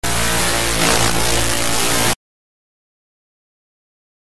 static.mp3